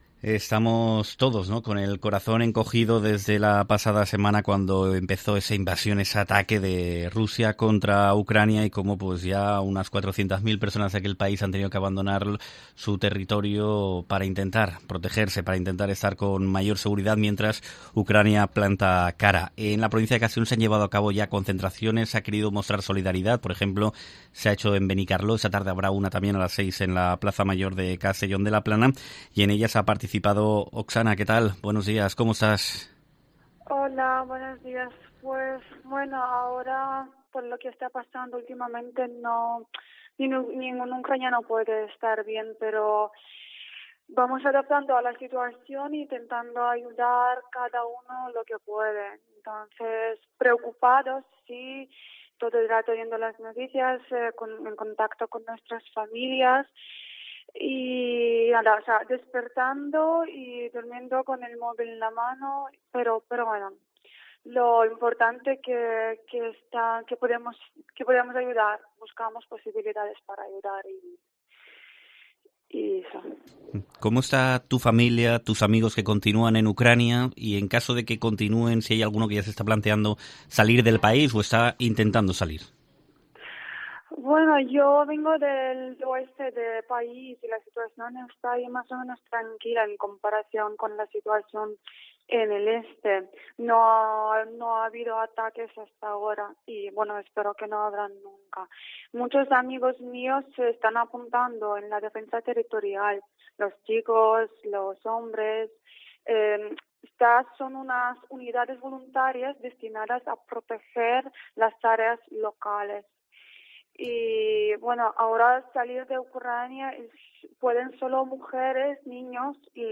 ucraniana en Benicarló, explica a COPE cómo se vive la guerra iniciada por Rusia